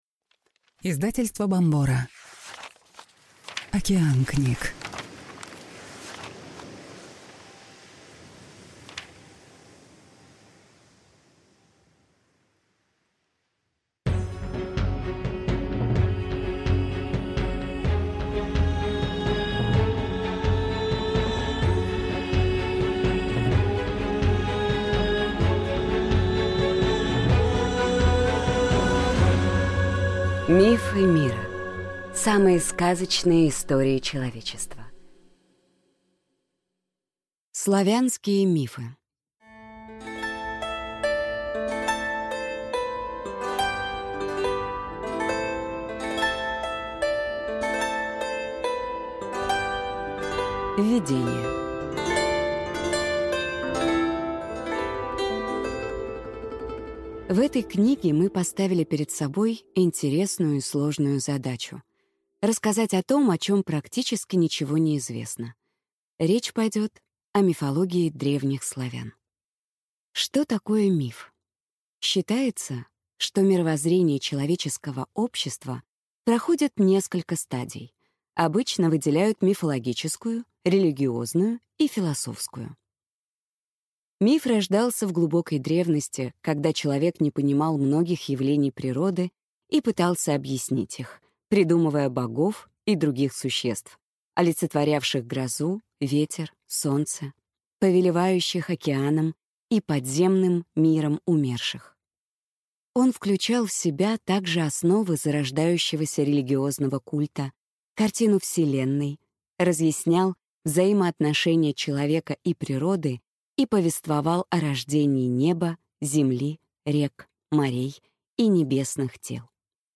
Аудиокнига Славянские мифы | Библиотека аудиокниг